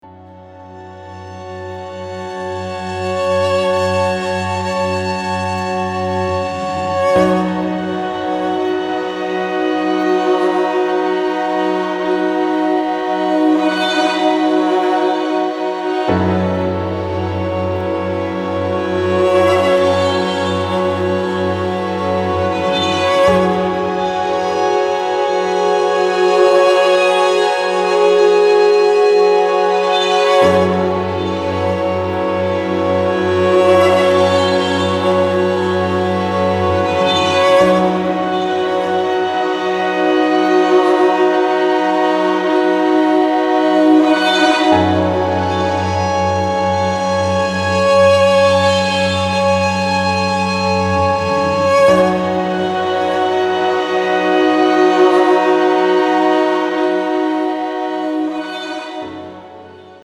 ジャンル(スタイル) DEEP HOUSE / JAZZY HOUSE